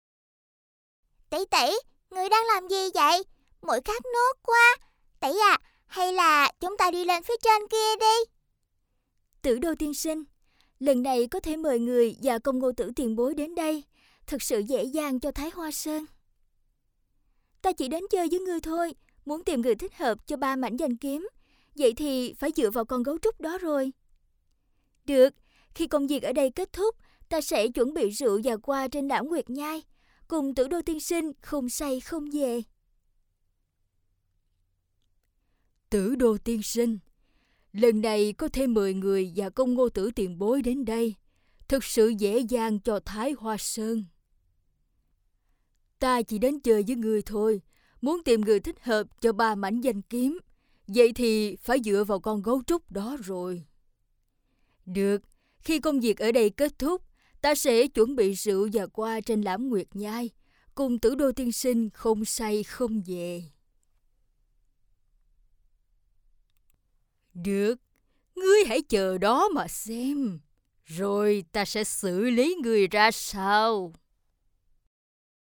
影视角色【甜美可爱】